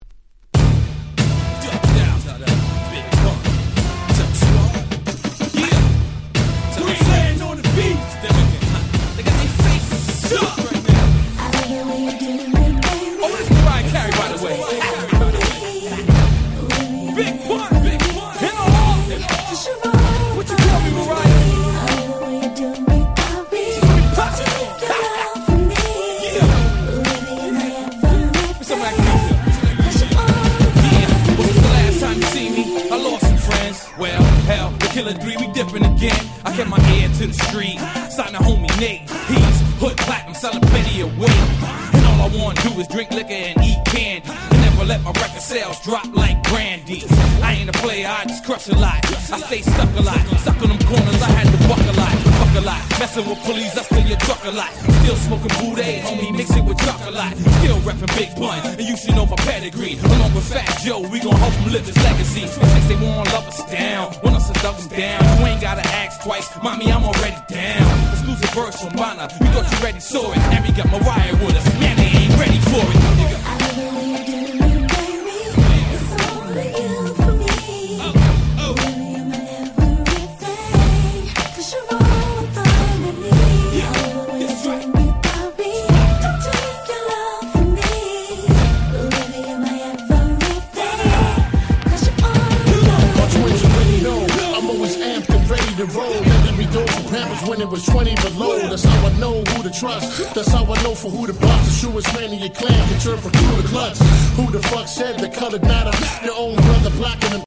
05' White Press Only R&B 12'' !!